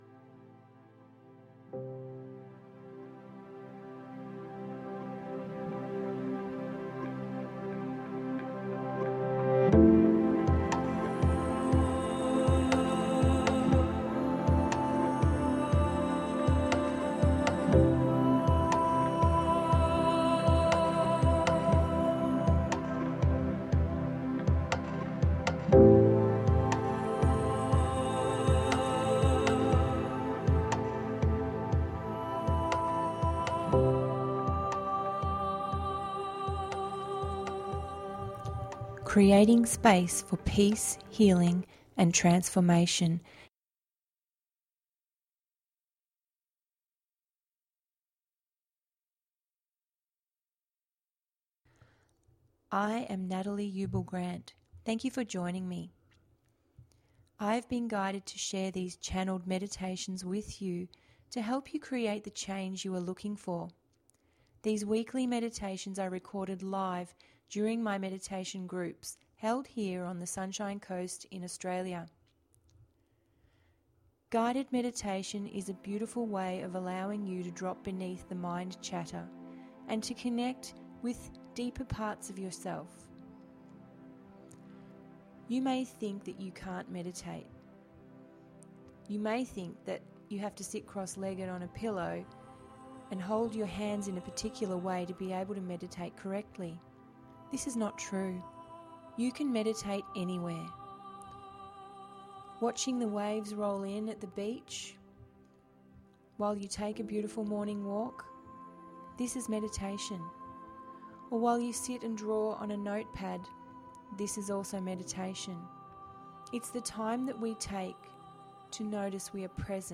Meditation duration approx. 22 mins